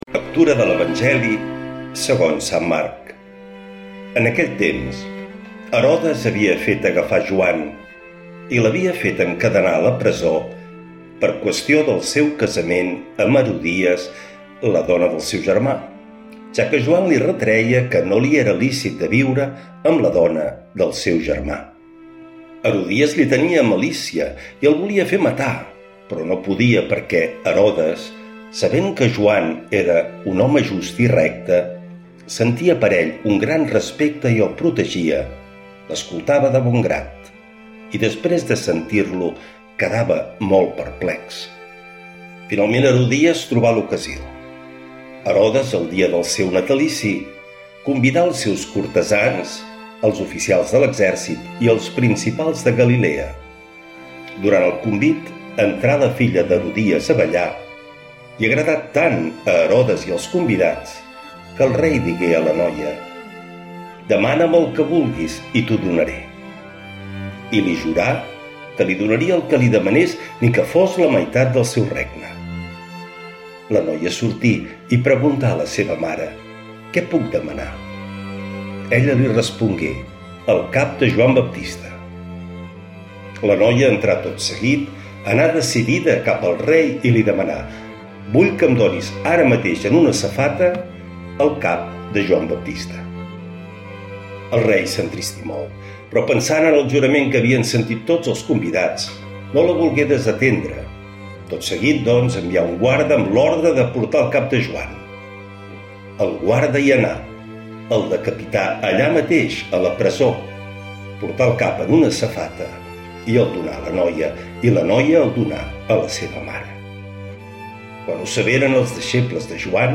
Lectura de l’evangeli segons sant Marc